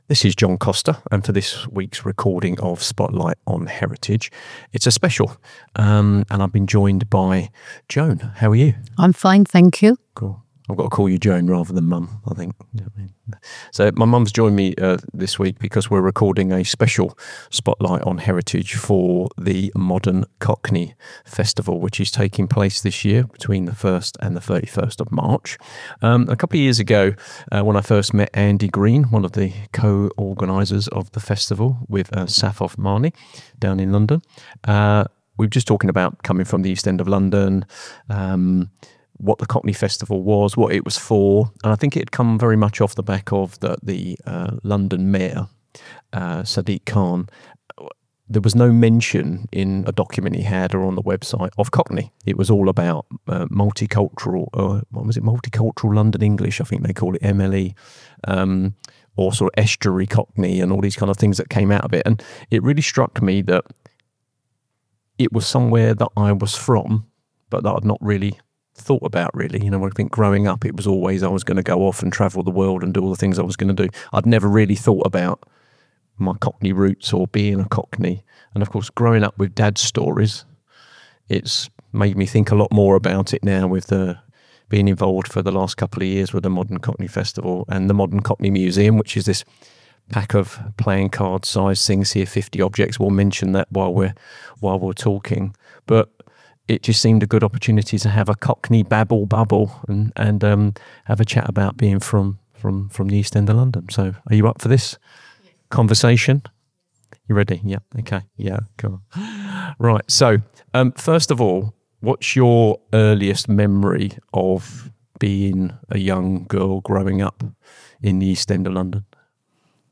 Recorded as an informal but deeply personal oral history, the episode explores memories of life in Stepney and Poplar during and after the Second World War.